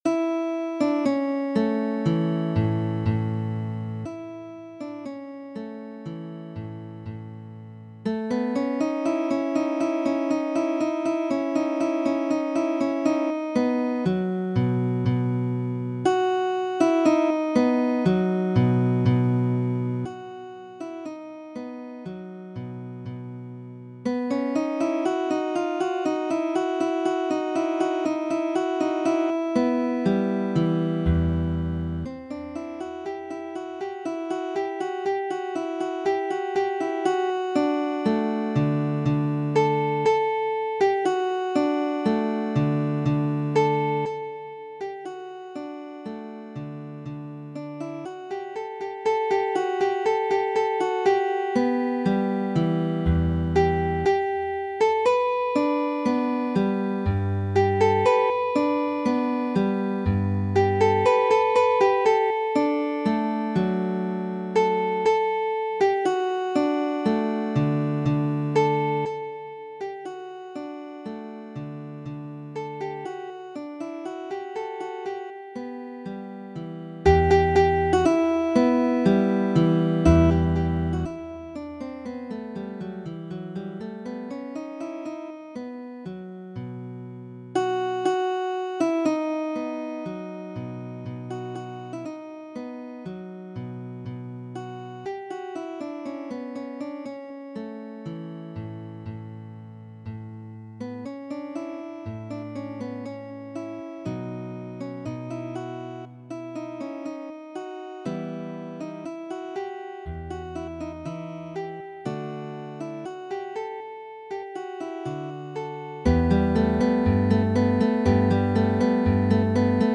Fantazio 10-a, kiu imitas harpon ludovikemaniere,